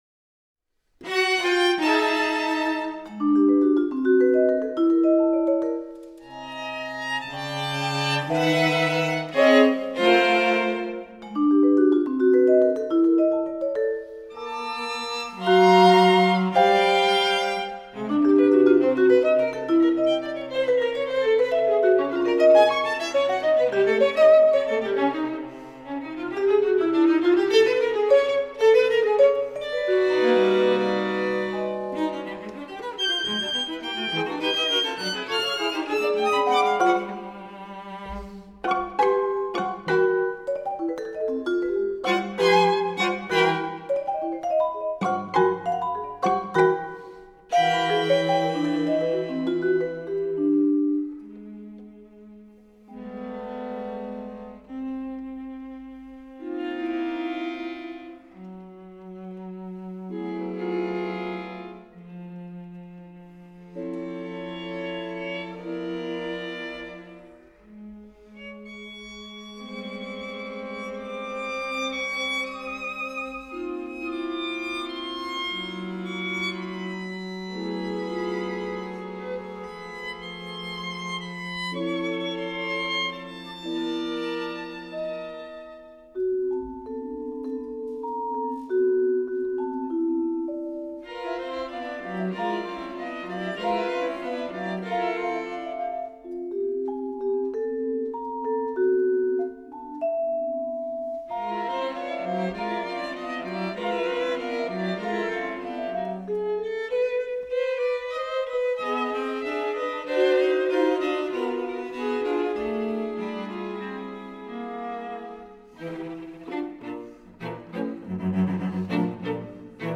Violine
Viola
Violoncello
Vibraphon